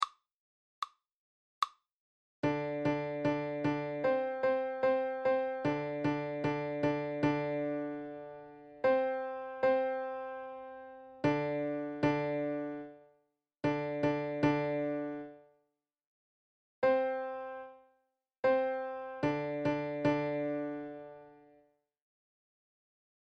Intervals Minor 7th Exercise 05